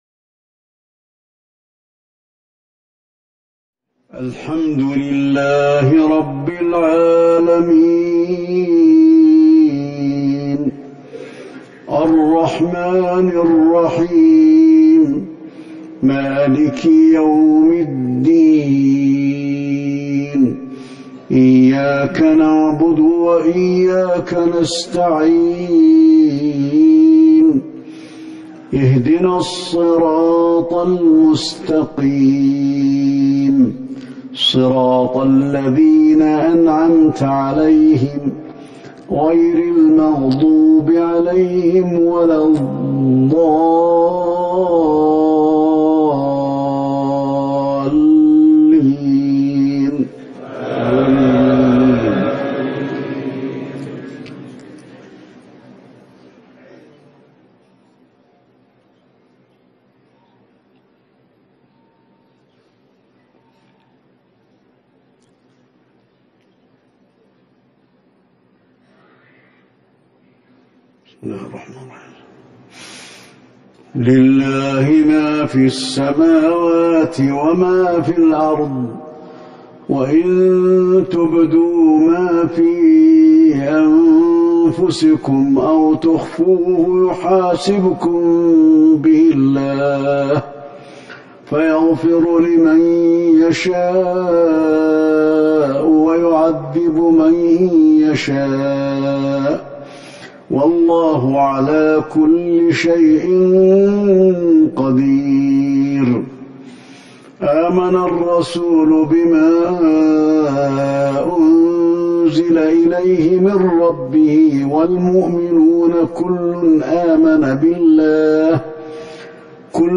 صلاة المغرب 7 جمادى الآخرة 1441 من سورتي البقرة والمؤمنون | Maghrib prayer 1-2-2020 from Surat Al-Baqara & Al Muminoon > 1441 🕌 > الفروض - تلاوات الحرمين